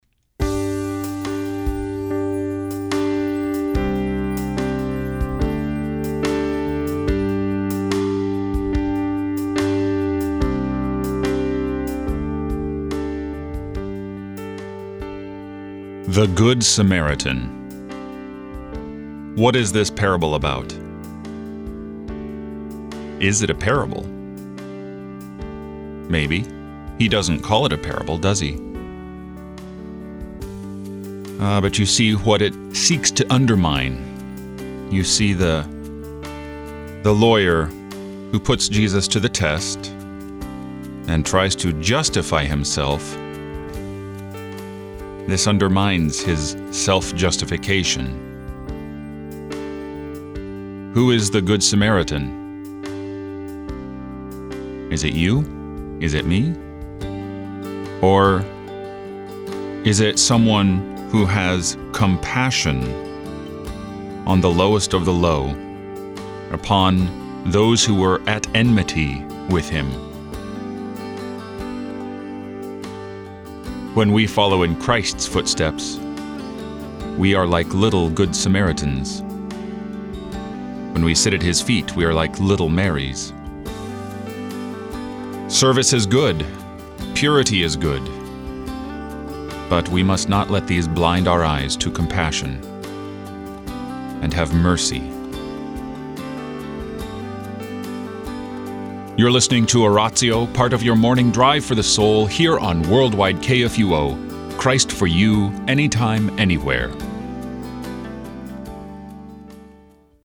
give meditations on the day’s scripture lessons.